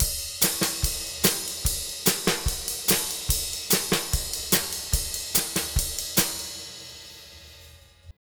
Twisting 2Nite 4 Drumz.wav